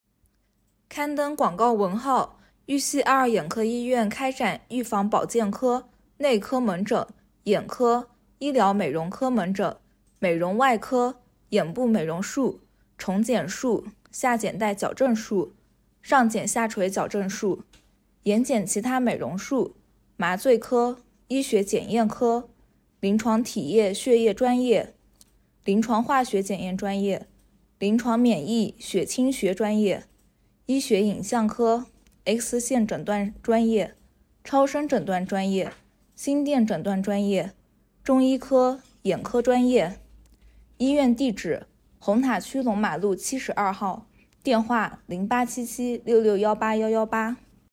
玉溪爱尔眼科医院广播.mp3